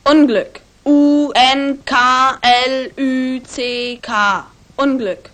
cb-m1-boy2.mp3